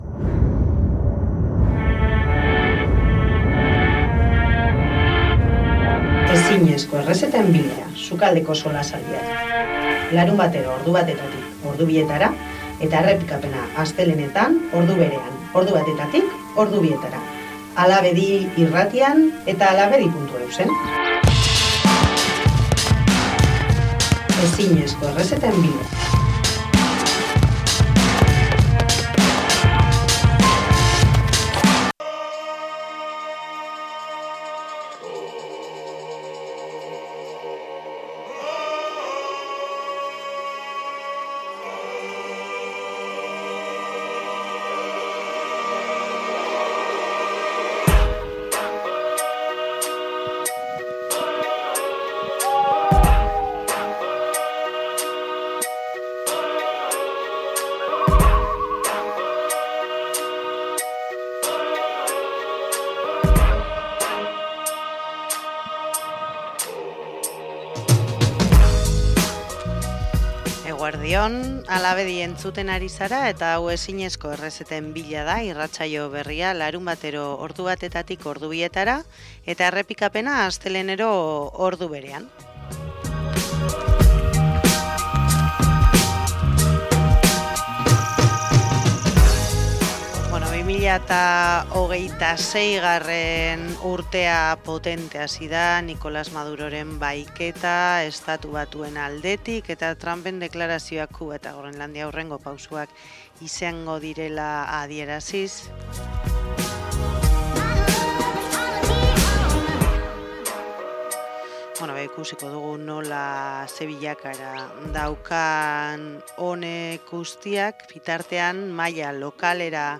Irratsaio honetan etxebizitzaren eskubidearen inguruan hitz egin dugu Gasteizko Alde Zaharreko Auzoan Bizi Etxebizitza Sindikatuko kideekin.